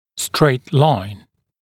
[streɪt laɪn][стрэйт лайн]прямая линия